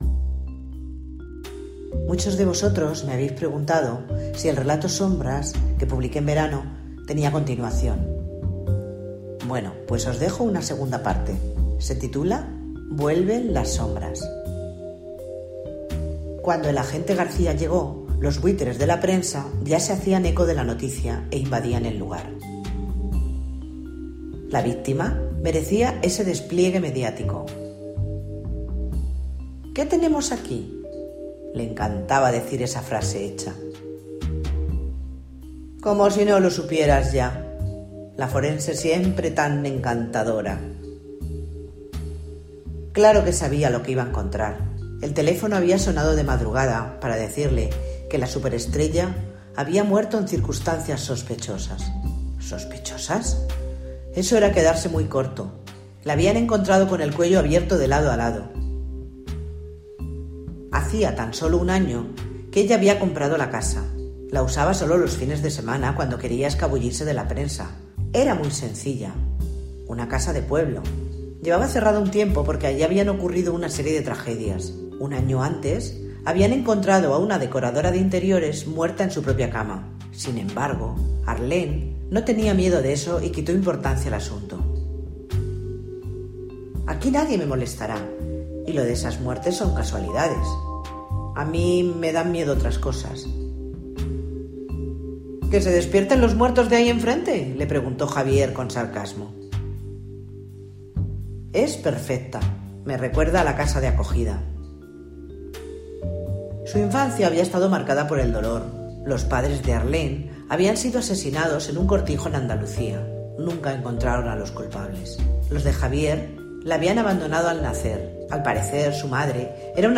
vuelven-las-sombras-con-musica.mp3